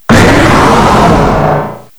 The cries from Chespin to Calyrex are now inserted as compressed cries